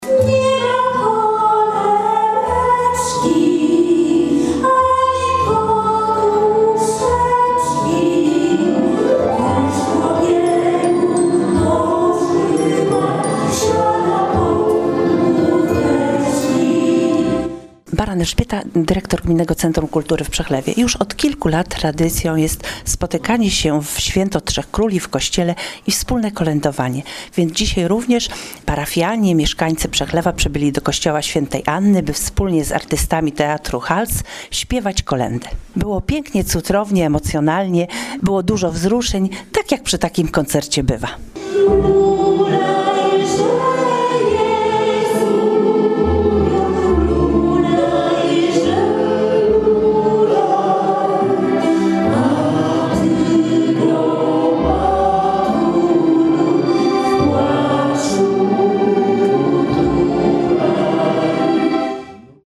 Najpiękniejsze polskie kolędy i piosenki świąteczne, można było usłyszeć w Przechlewie.
Koncert odbył się w kościele p.w. Św. Anny.
Widzowie są zapraszani do wspólnego śpiewania.